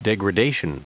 Prononciation du mot degradation en anglais (fichier audio)
Prononciation du mot : degradation